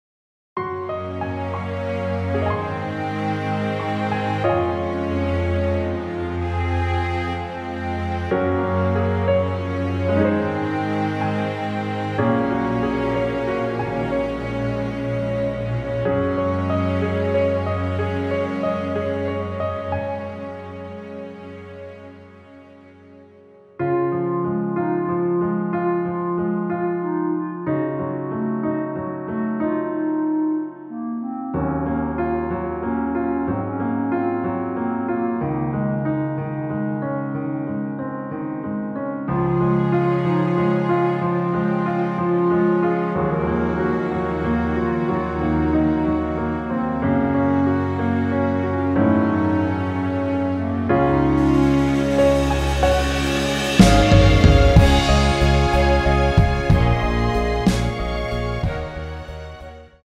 원곡보다 짧은 MR입니다.(아래 재생시간 확인)
원키 (짧은편곡)멜로디 포함된 MR입니다.
Db
앞부분30초, 뒷부분30초씩 편집해서 올려 드리고 있습니다.
중간에 음이 끈어지고 다시 나오는 이유는